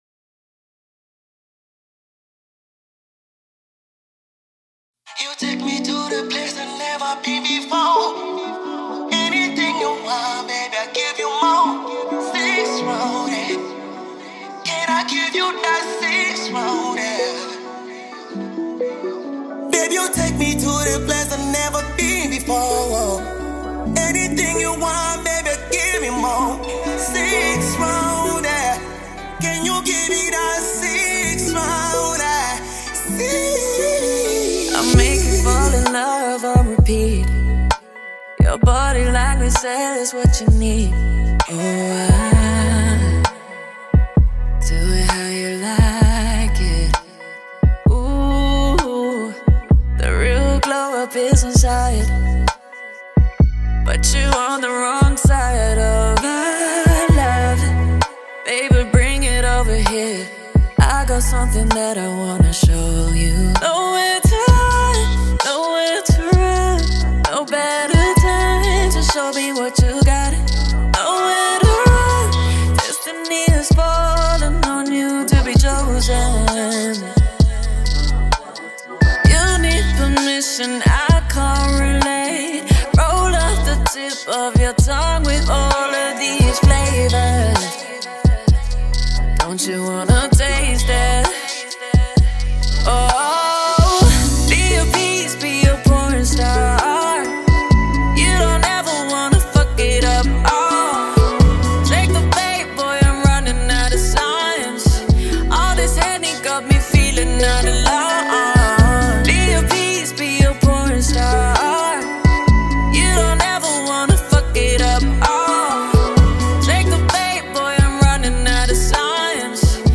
AudioR&B
smooth Afro-Pop/Bongo Flava single